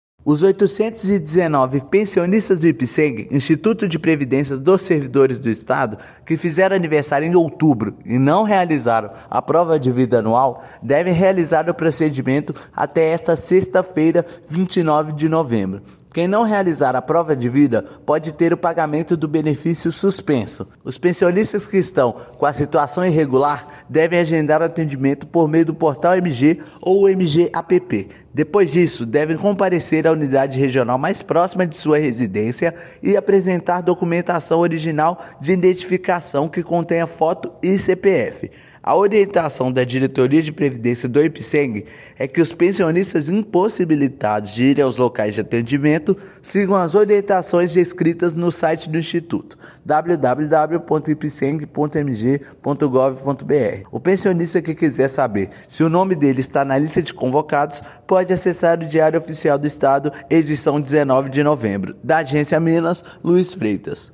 Procedimento deve ser feito de forma presencial em uma das unidades regionais do instituto. Ouça matéria de rádio.